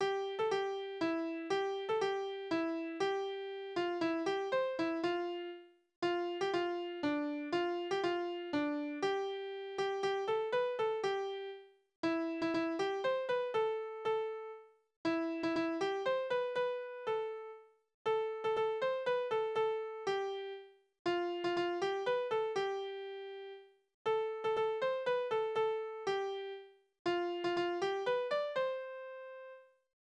Tonart: C-Dur
Taktart: 3/4
Tonumfang: Oktave
Besetzung: vokal
Anmerkung: Stückbezeichnung: Tanz